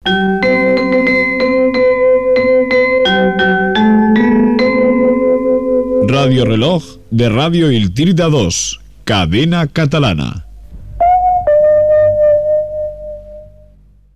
Sintonia, indicatiu de l'emissora i toc horari